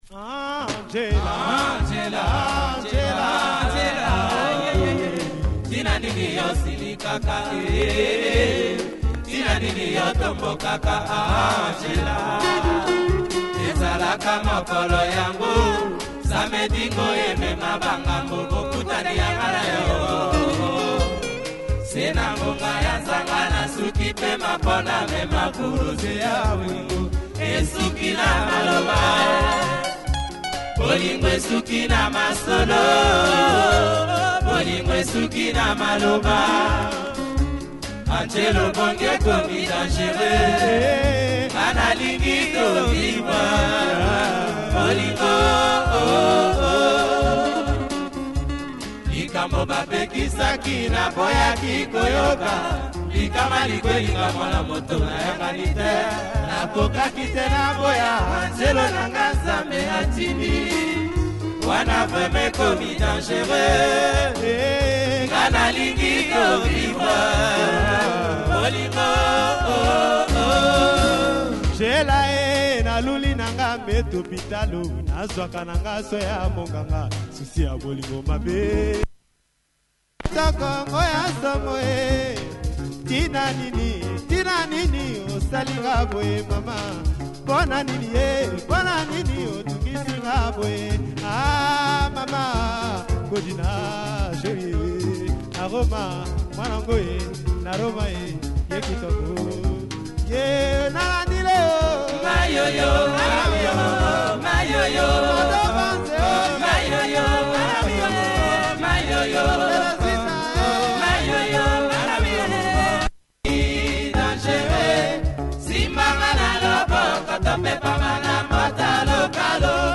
Lingala